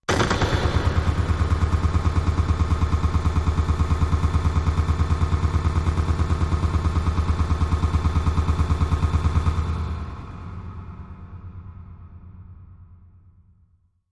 Simple Pulse, Machine, Space Electric Motor, Pulse